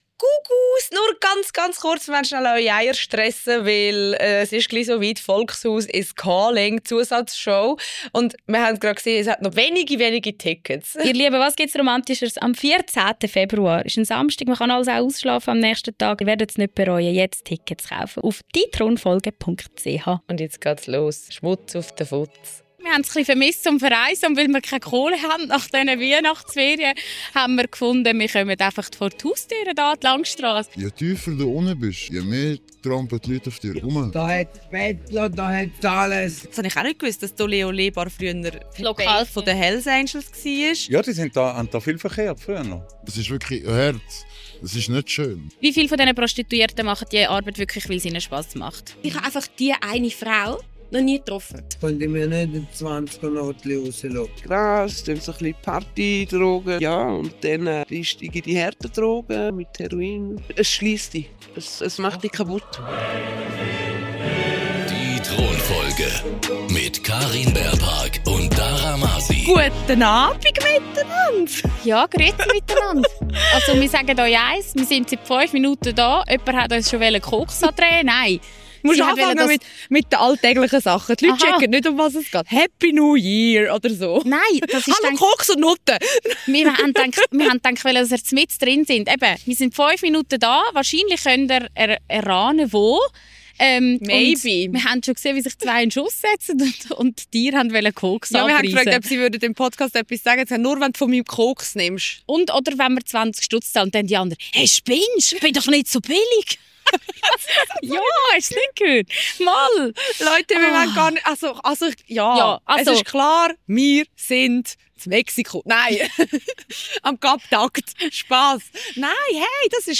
An der Zürcher Langstrasse leben Heroinabhängige, Prostituierte und Feierwütige mal friedlicher, mal weniger friedlich nebeneinander. Mit ihren Thrönen auf dem Rücken und bei Minustemperaturen verbringen die Mütter eine Nacht draussen und nehmen euch natürlich mit.